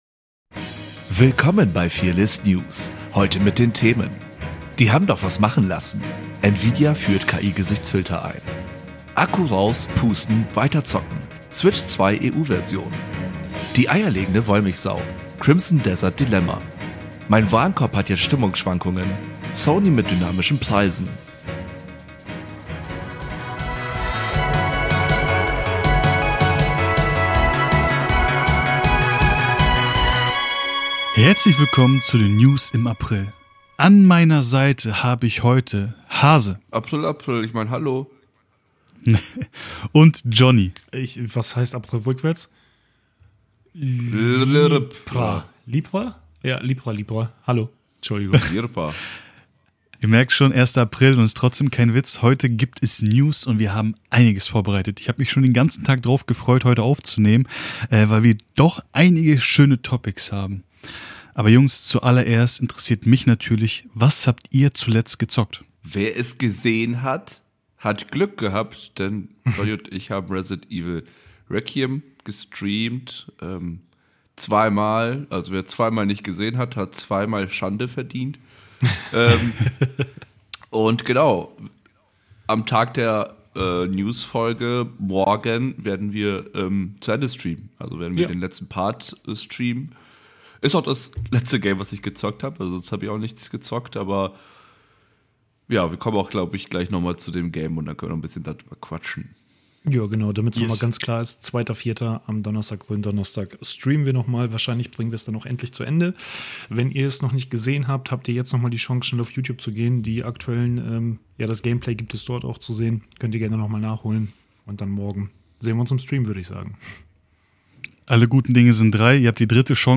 Epic 16-Bit Music
16-Bit Synthwave